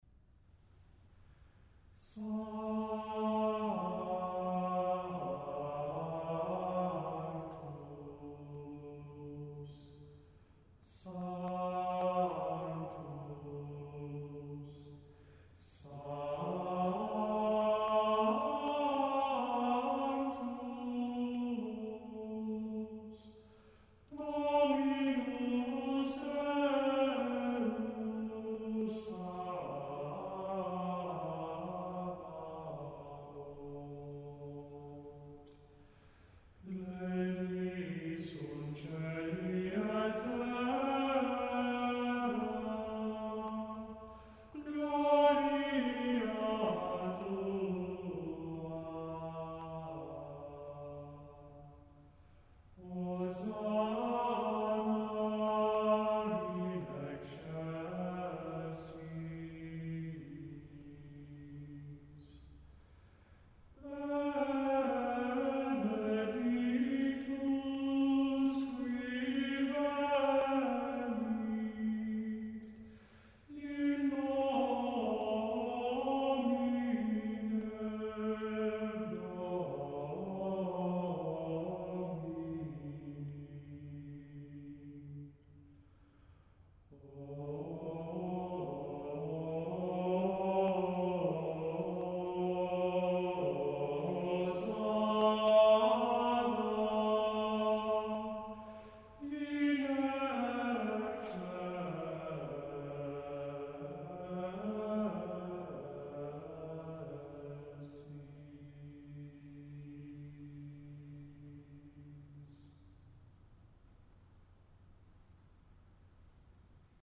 Gregoriaans
Sanctus Melismatisch of syllabisch?